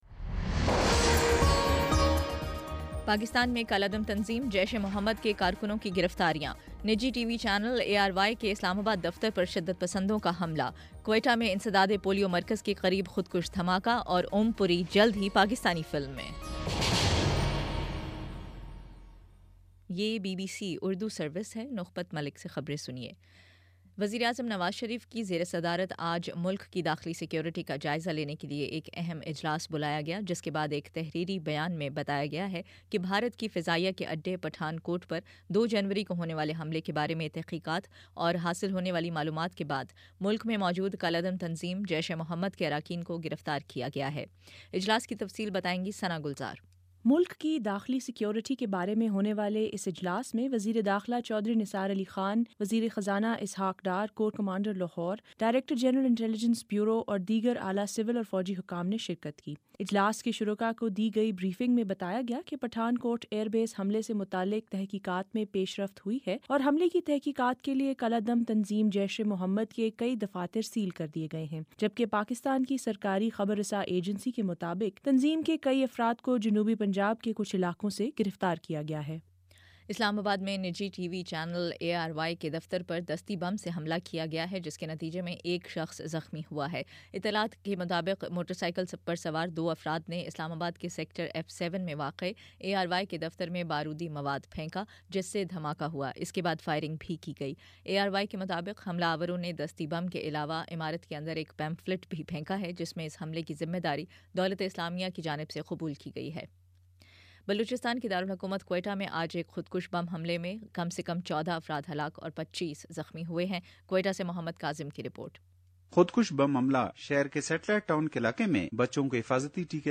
جنوری 13 : شام سات بجے کا نیوز بُلیٹن